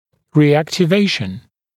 [rɪˌæktɪ’veɪʃn][риˌэкти’вэйшн]повторная активация